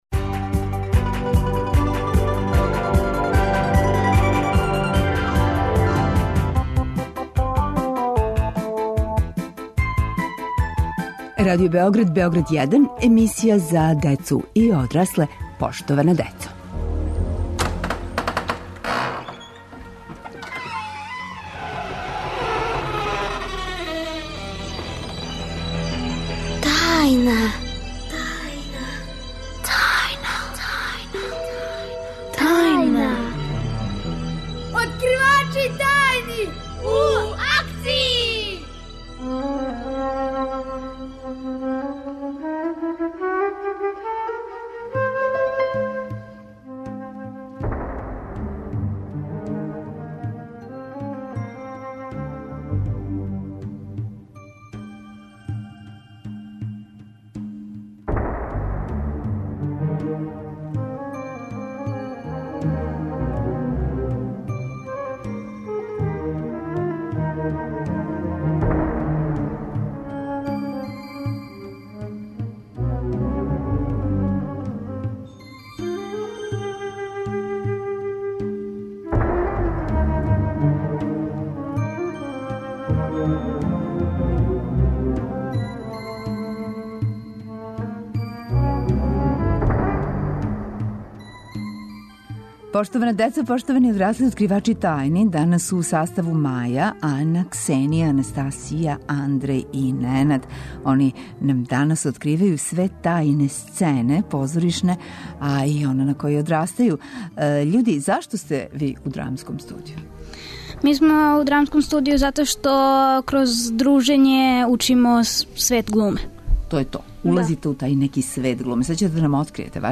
Гости су нам деца, чланови драмских група, они нам откривају тајне сцене - чему нас све позориште учи.